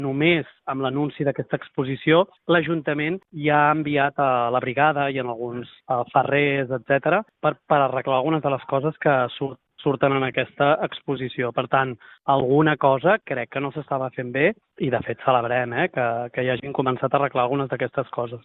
Són declaracions a Ràdio Calella TV.